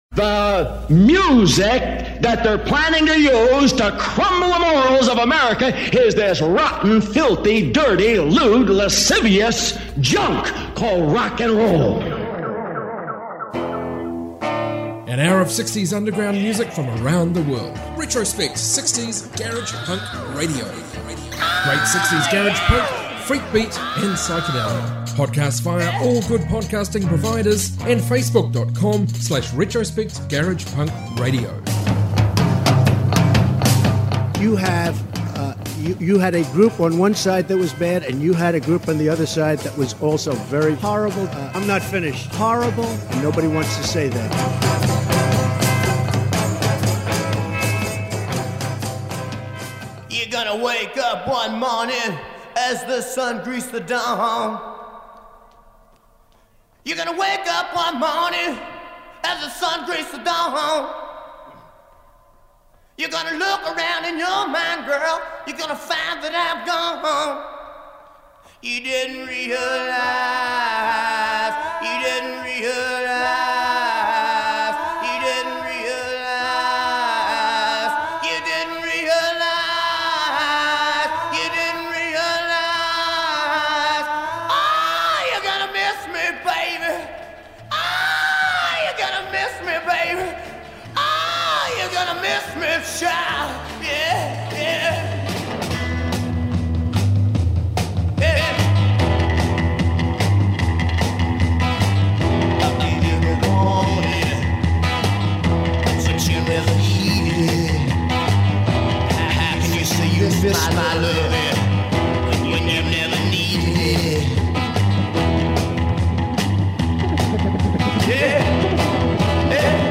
60s garage punk